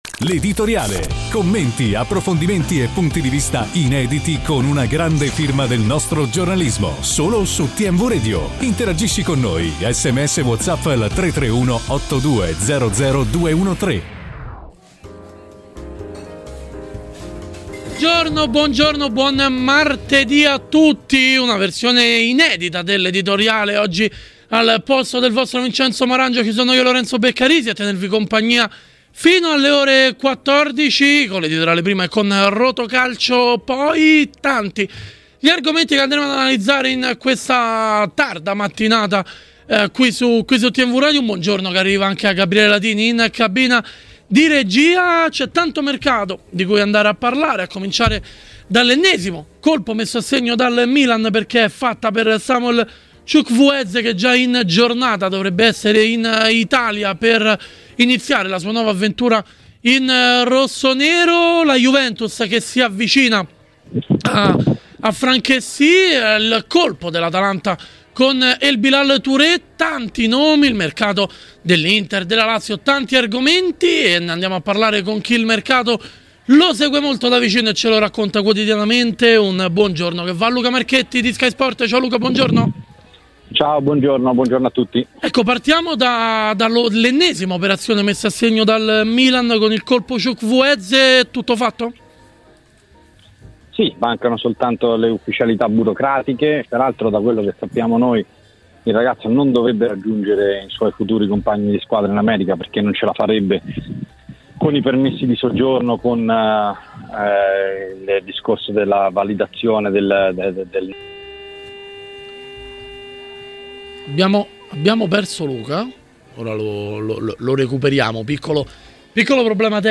Intervenuto ai microfoni di Tmw Radio